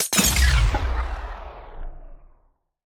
Minecraft Version Minecraft Version snapshot Latest Release | Latest Snapshot snapshot / assets / minecraft / sounds / block / respawn_anchor / deplete2.ogg Compare With Compare With Latest Release | Latest Snapshot